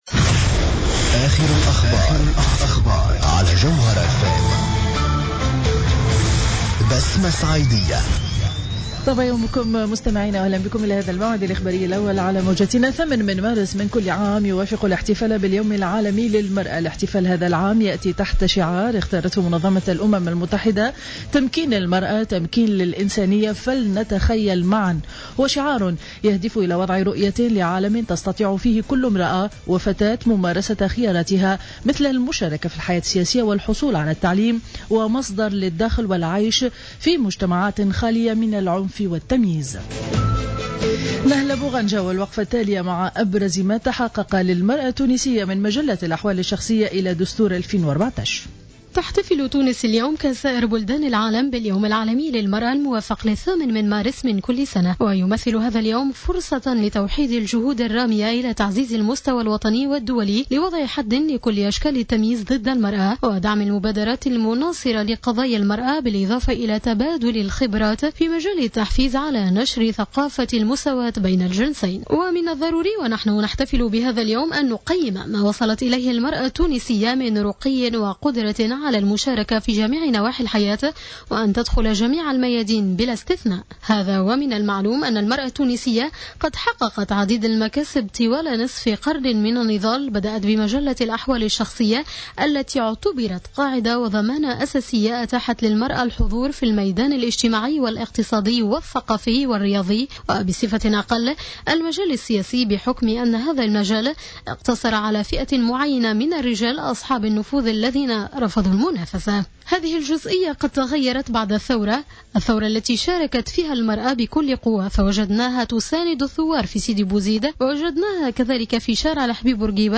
نشرة أخبار السابعة صباحا ليوم الأحد 08 مارس 2015